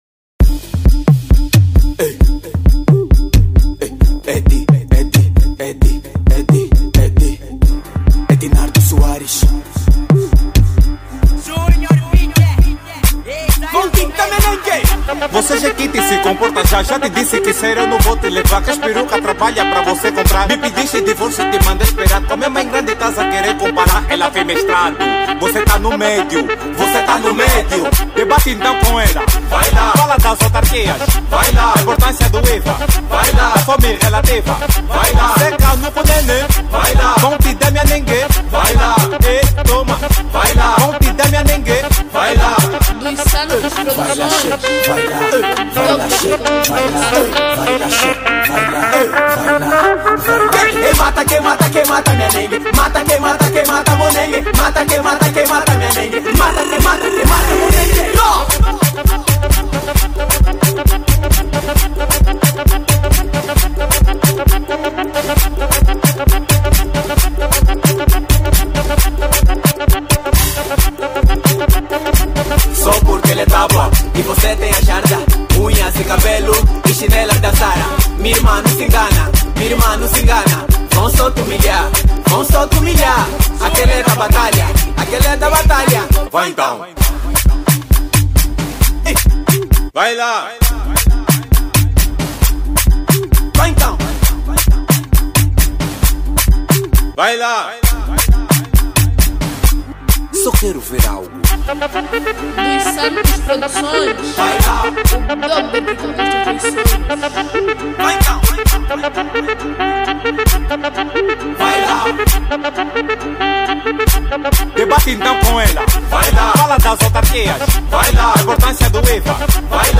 Categoria Afro House